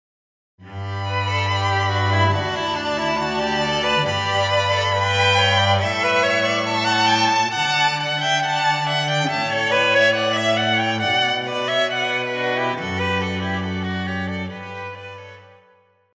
Classical
Cello,Violin
Chamber
Quintet